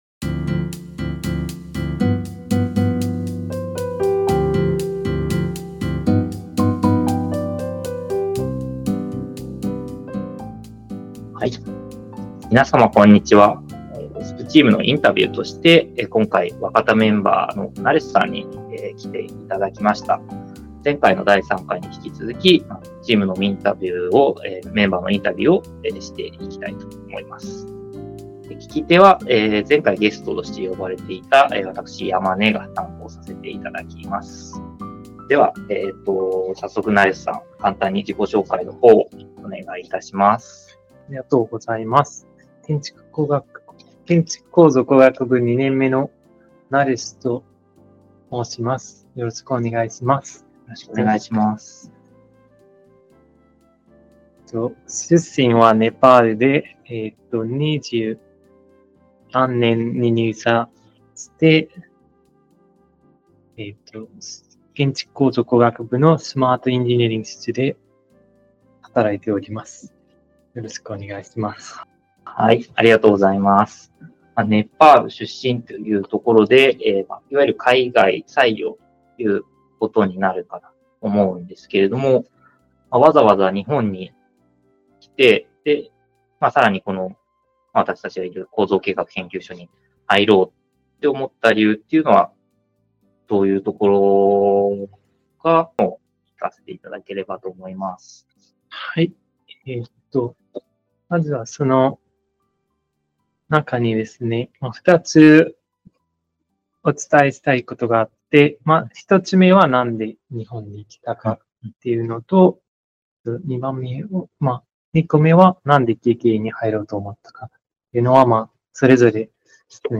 今回もほぼノーカットとしております。
※手探りでやっているため進行にたどたどしいところがありますが、お付き合いいただけると幸いです。